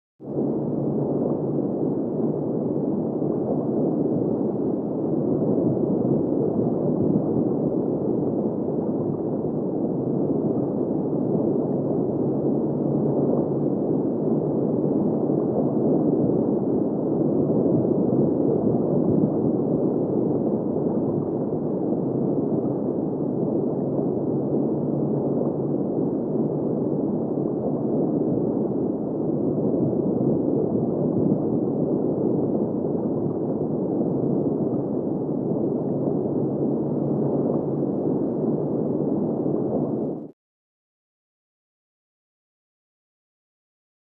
Heavy Rumble Underwater 2; With Roar And Bubbles From The Deep Sea.